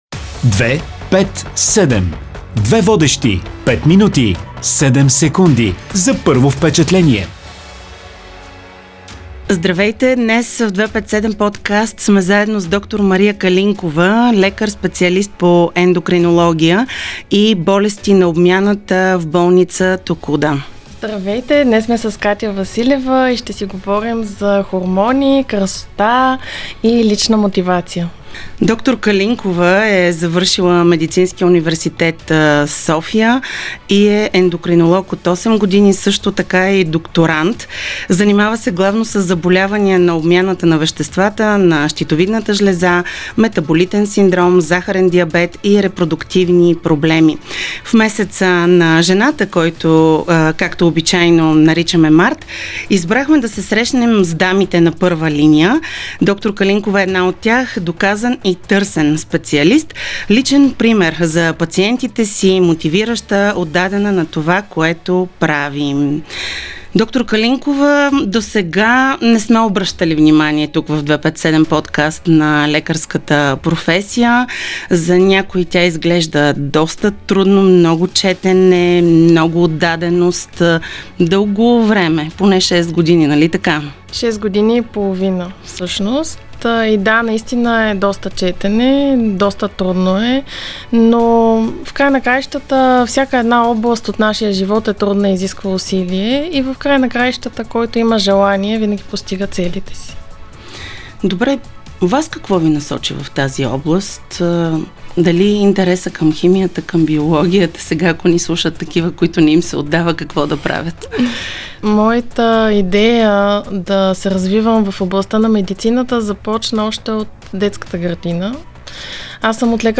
интервюто